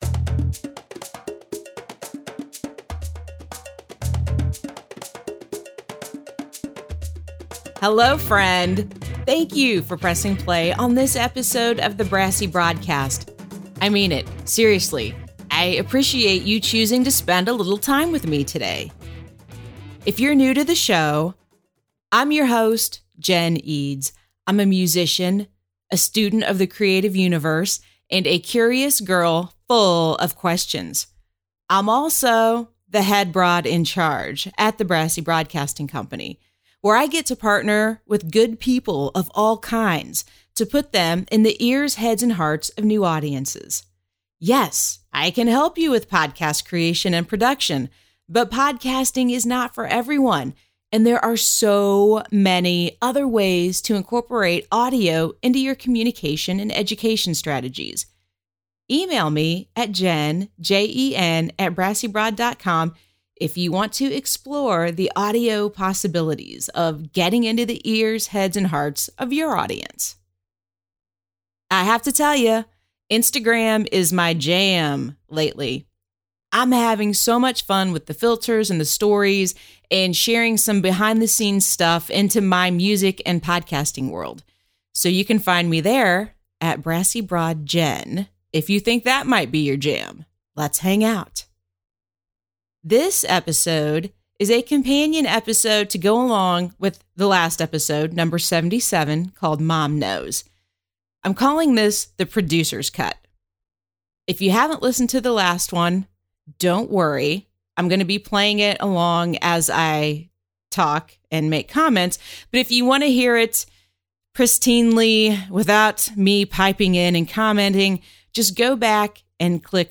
I recorded this story using an Audio Technica ATR2100, plugged into my iPhone 6s.
I recorded it while sitting in my walk-in closet, the tiniest walk-in closet known to man.